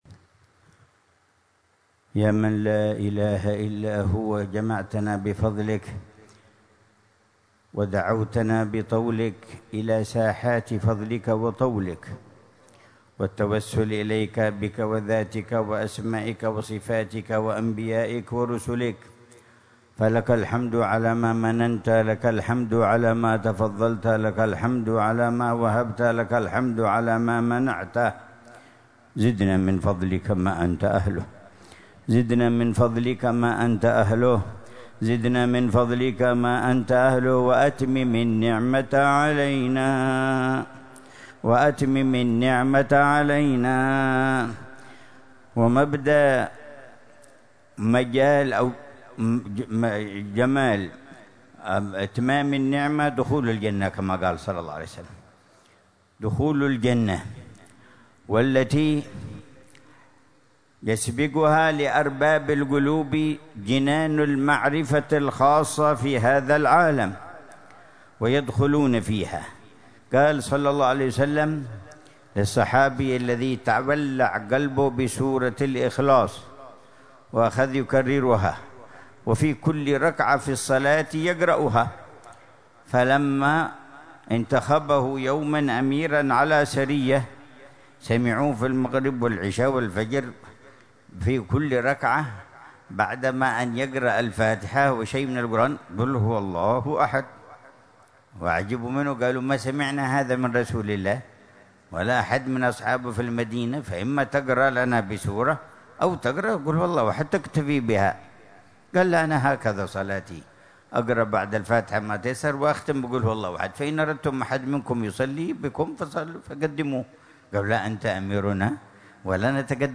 مذاكرة العلامة الحبيب عمر بن محمد بن حفيظ في مجلس الذكر والتذكير في شعب النبي هود عليه السلام ليلة الثلاثاء 6 محرم 1447هـ، بعنوان: